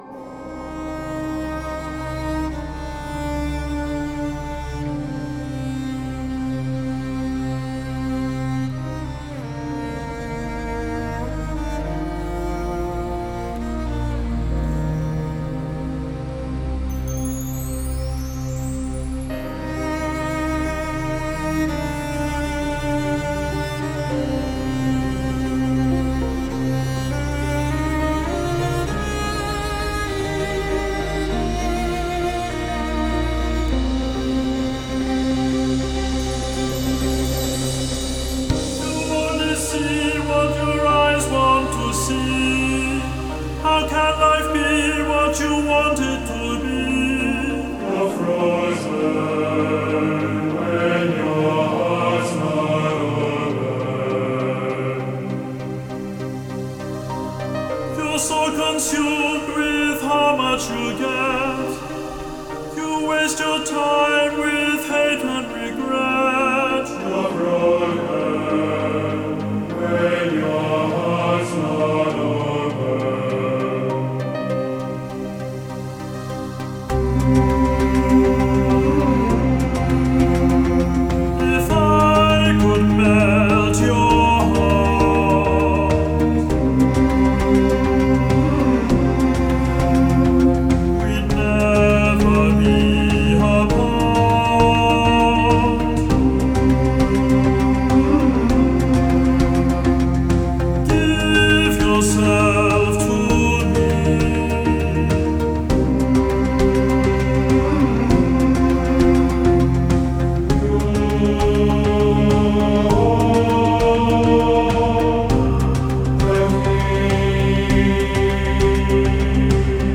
Нью-эйдж